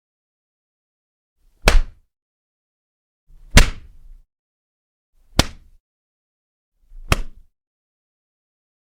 Paddle Board Bottom Hits
SFX
Paddle Board Bottom Hits is a free sfx sound effect available for download in MP3 format.
yt_cTrNDCbQg_o_paddle_board_bottom_hits.mp3